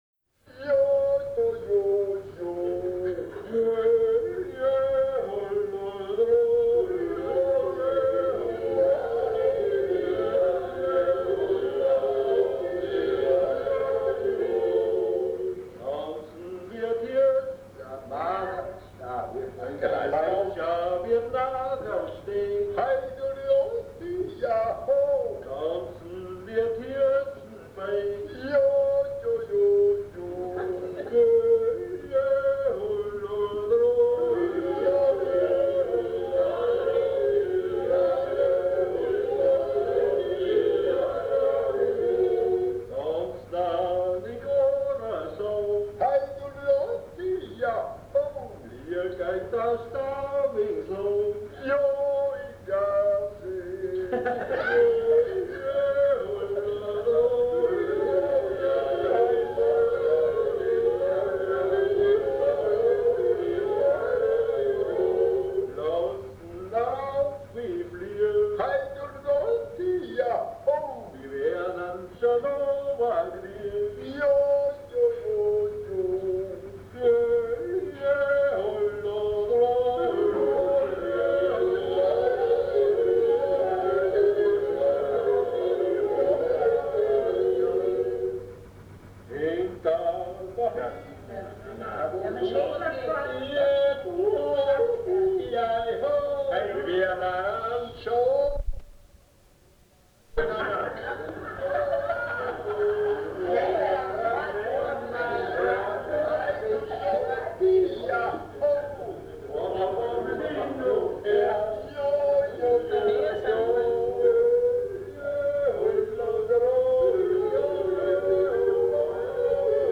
Jodler, Jodler-Lied, Gstanzl und Tanz
Folk & traditional music